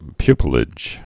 (pypə-lĭj)